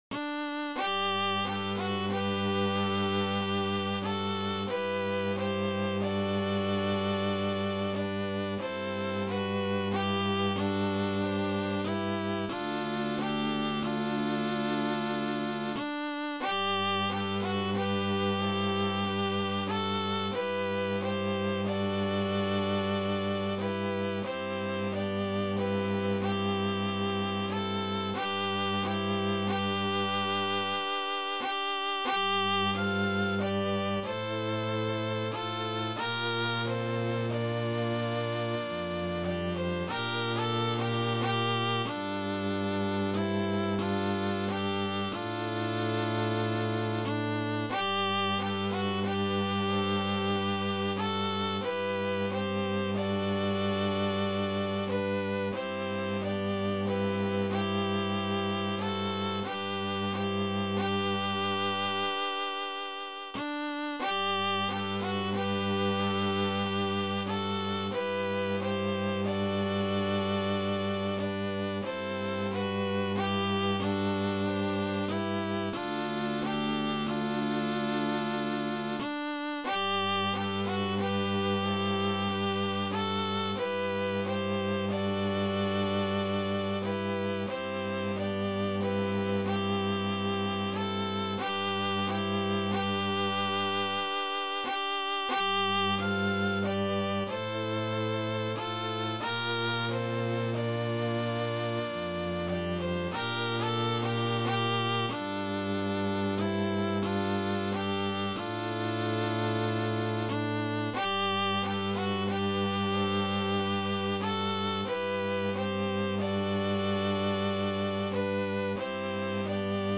It's just a midi file with general midi instruments.
However, I made a quick MP3 with the 2nd, 3rd, and 4th parts diminished so as to hear the melody more and I added a very basic bass line.
There are no tempo fluctuations as it's hard to do with a group project.